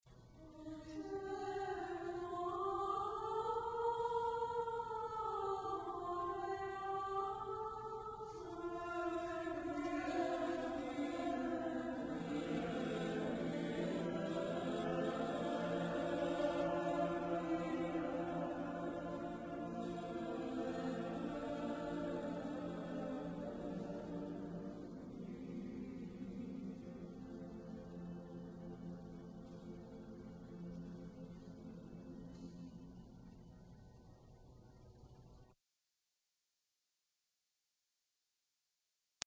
Voicing: ssaattbb Accompaniment: a cappella Language